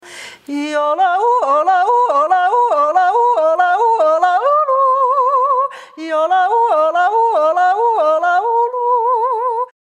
Le yodel «Tröhl»
yodel